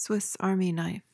PRONUNCIATION:
(swis AHR-mee nyf)